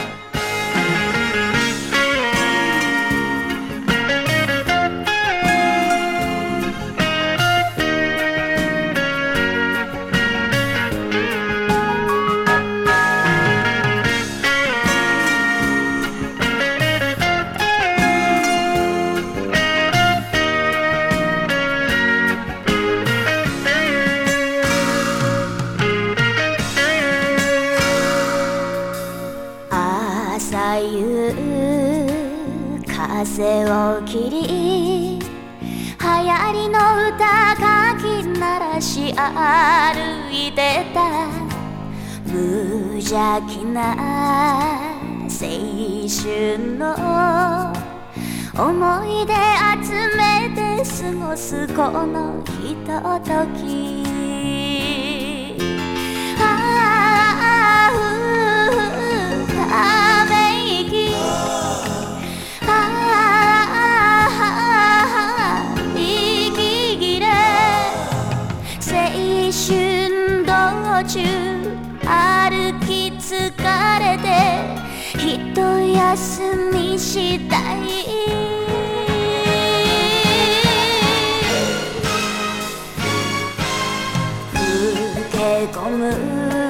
「こぶし」が効きつつ妖艶な歌声が魅力的な昭和歌謡！